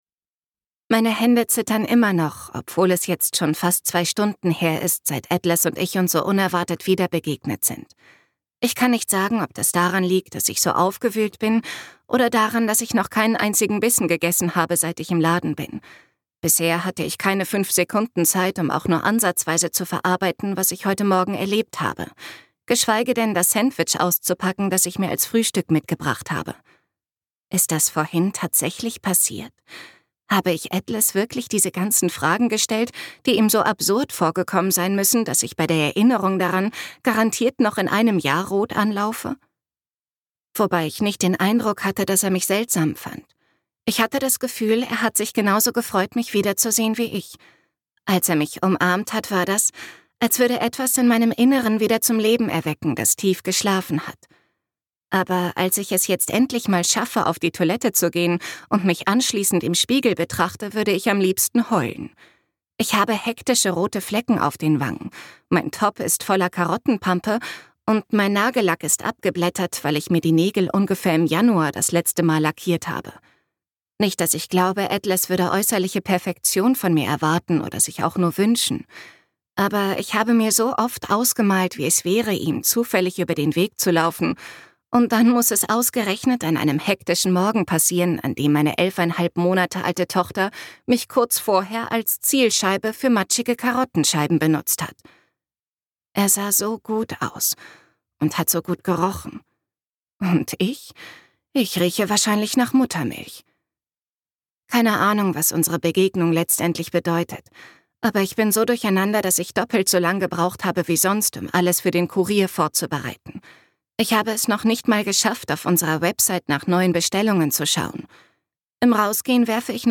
Nur noch einmal und für immer (DE) audiokniha
Ukázka z knihy